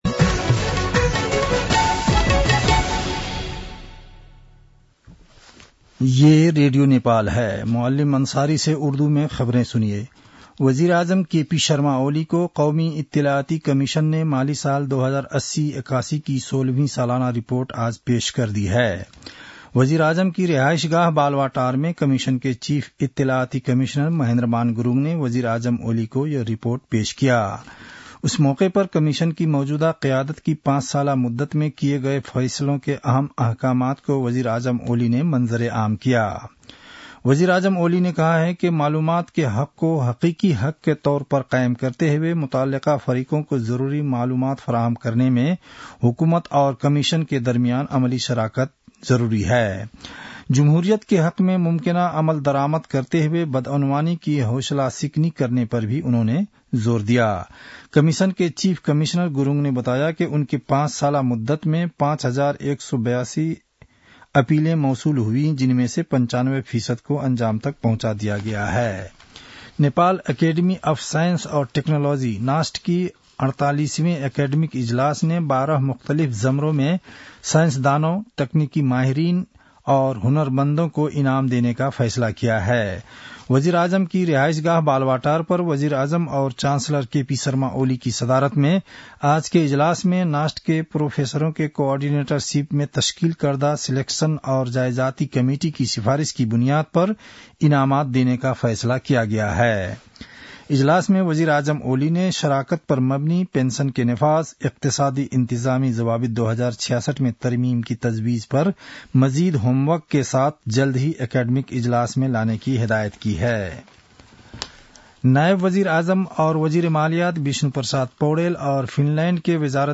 उर्दु भाषामा समाचार : १४ मंसिर , २०८१
Urdu-news-8-13.mp3